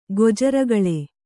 ♪ gojaragaḷe